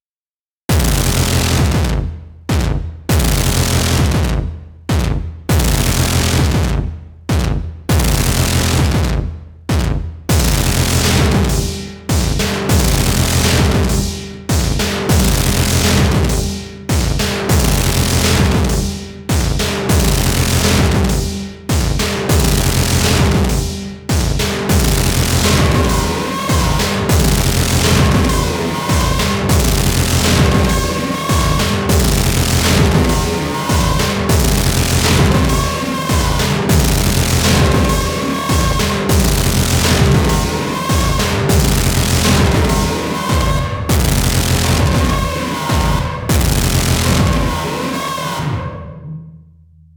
(Hihat is pretty meh, I have to investigate further how to make metallic cymbals on the A4.)